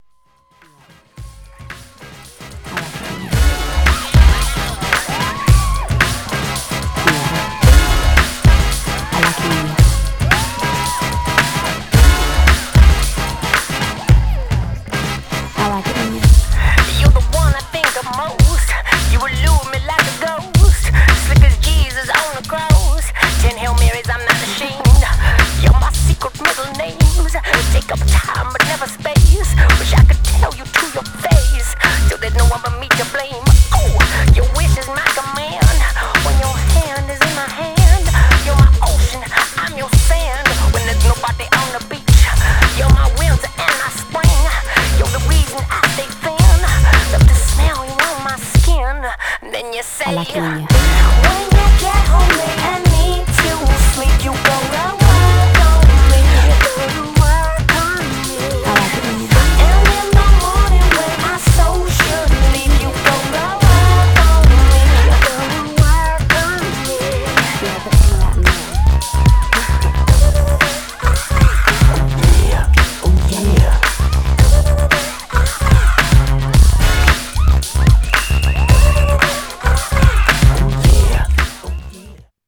Styl: Hip Hop, House, Breaks/Breakbeat